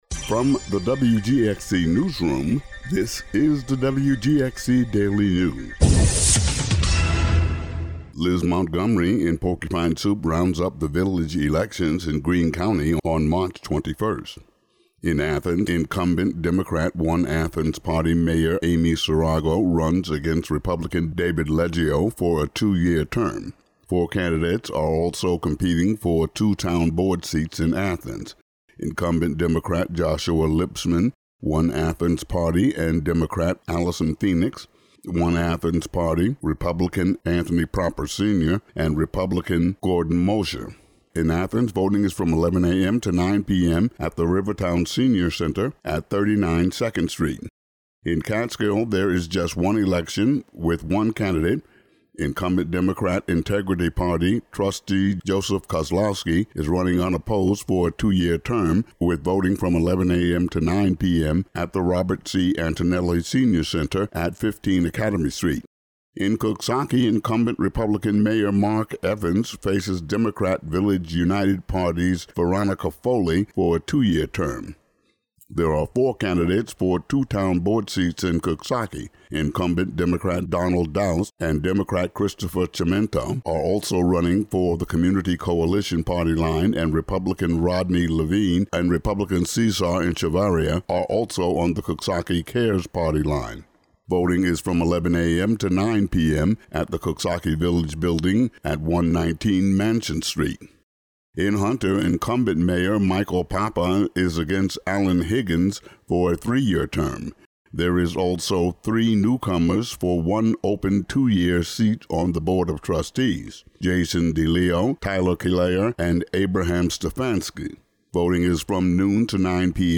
Today's daily news audio update.